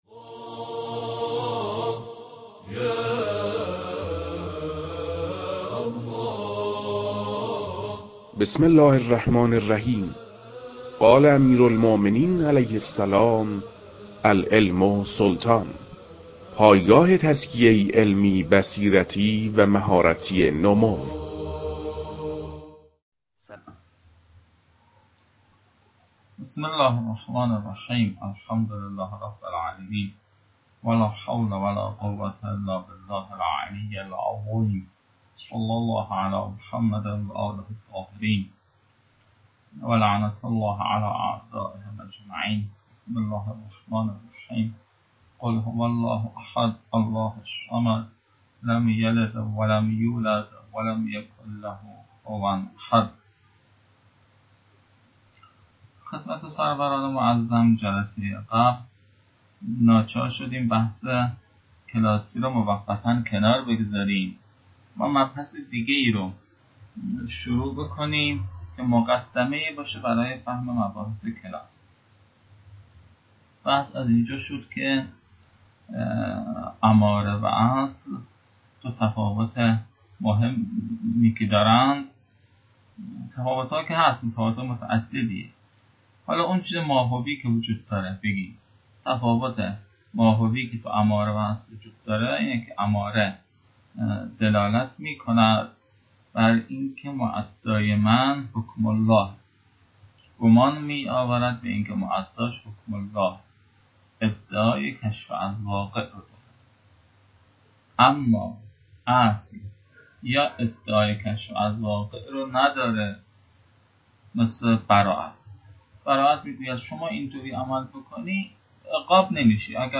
در این بخش، فایل های مربوط به تدریس مبحث رسالة في القطع از كتاب فرائد الاصول